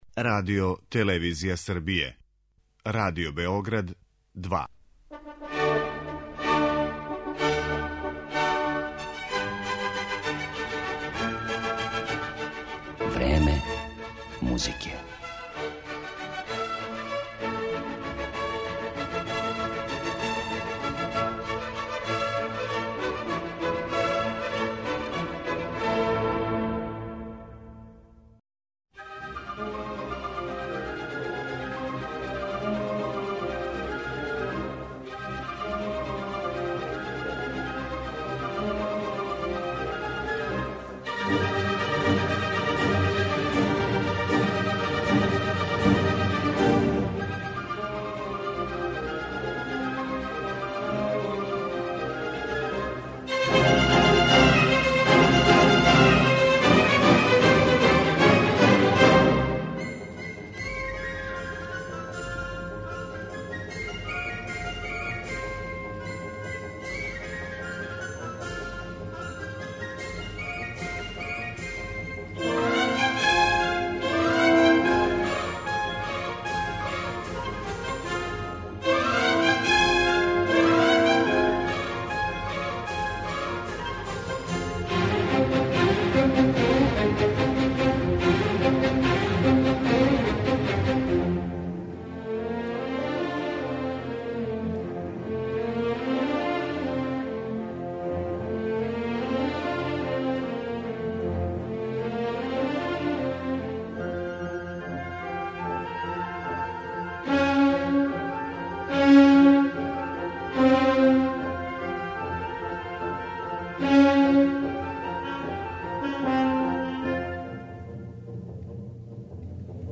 Балетски фрагменти из Вердијевих опера.